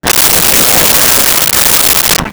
Crowd Laughing 08
Crowd Laughing 08.wav